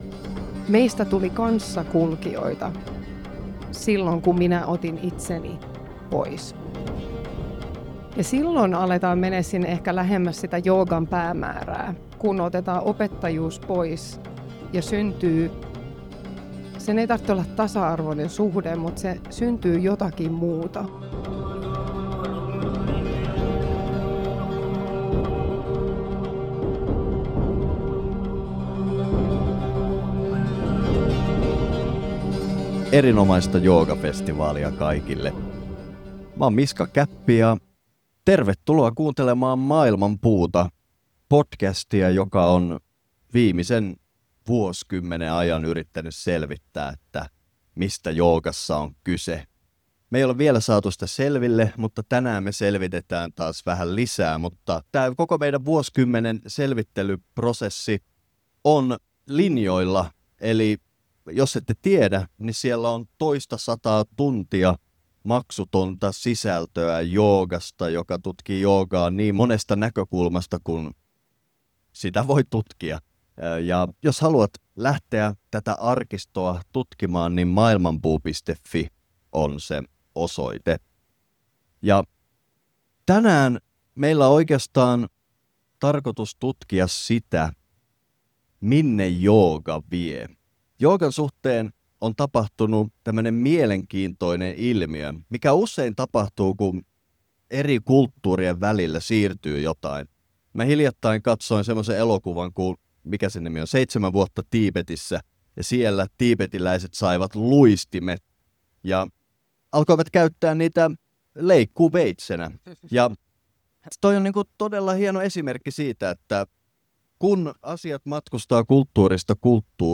Vuoden 2026 Helsinki Joogafestival -tapahtumassa Maailmanpuu juonsi paneelikeskustelun, jossa kaivauduimme syvälle joogan olemusta koskeviin kysymyksiin.
Tervetuloa mukaan vapaasti virtaavaan livekeskusteluun.